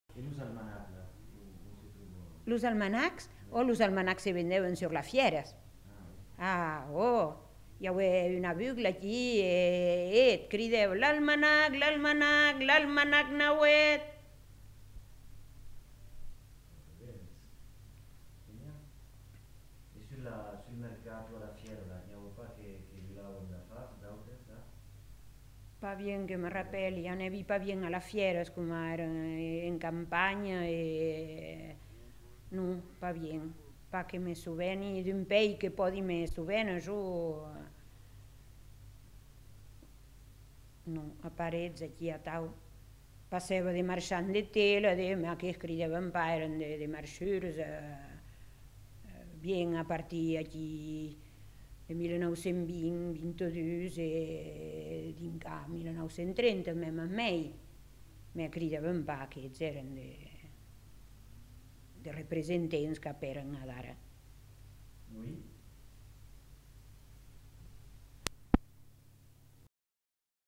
Cri de métier du vendeur d'almanach